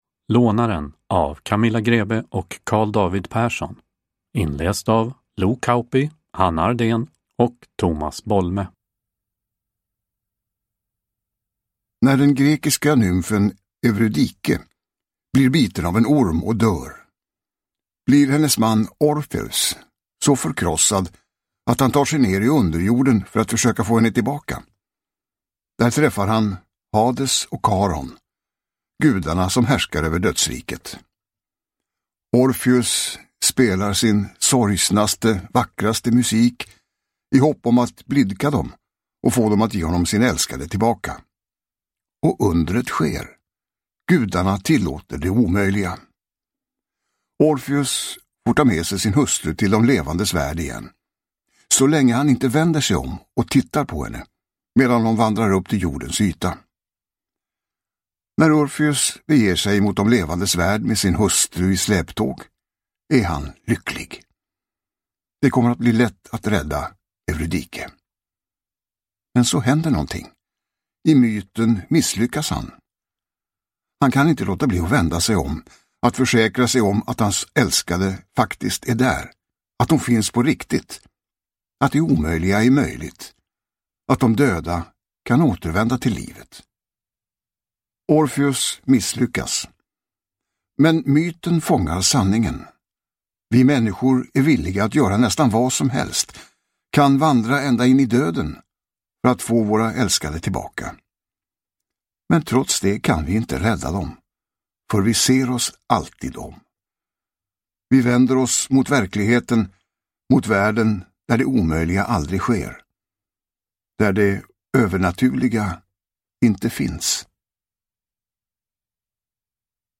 Lånaren – Ljudbok – Laddas ner